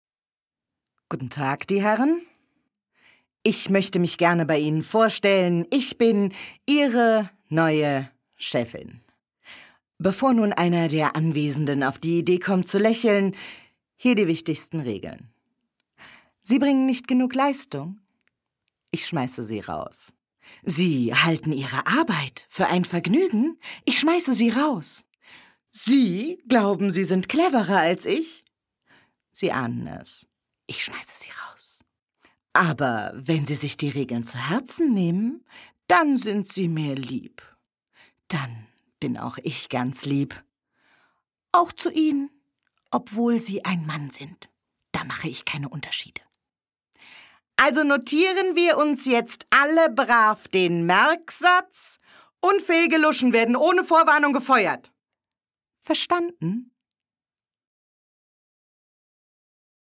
Eine Stimme, so warm, fesselnd, glaubwürdig und so angenehm, dass man sich von ihr umarmen lassen möchte.
Sprechprobe: Sonstiges (Muttersprache):